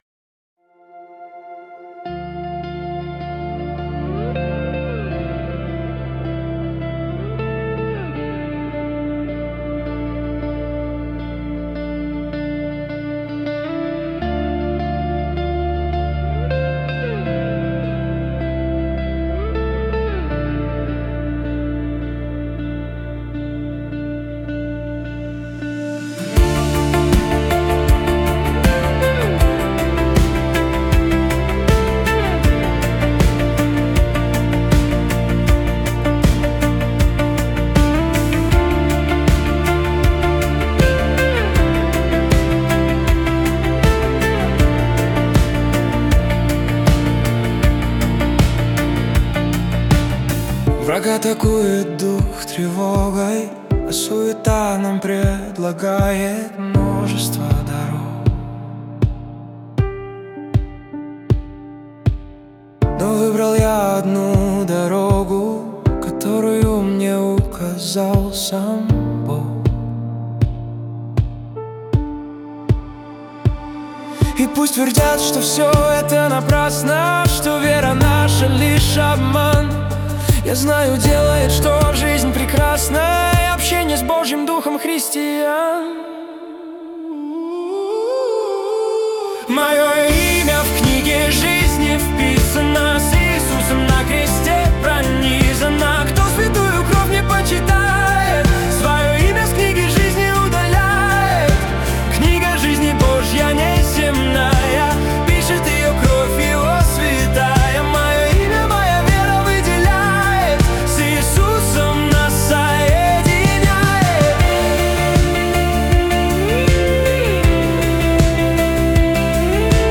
песня ai
126 просмотров 647 прослушиваний 39 скачиваний BPM: 79